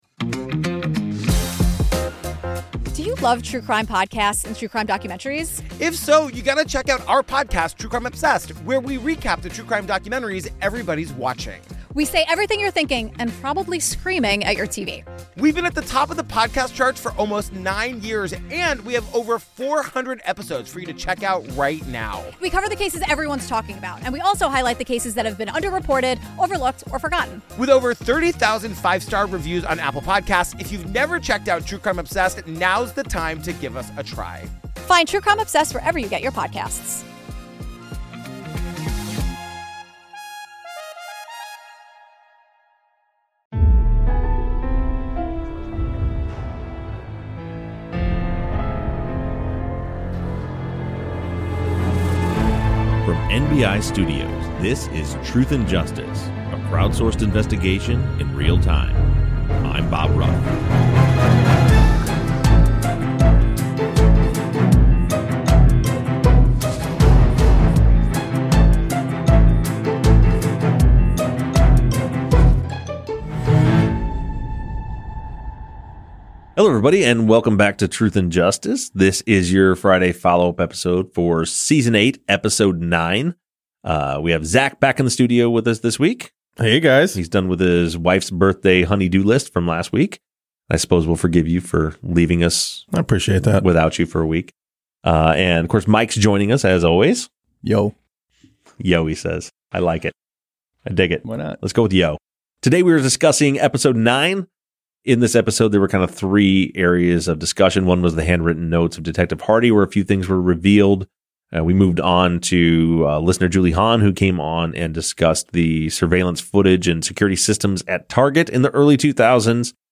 the guys discuss listener questions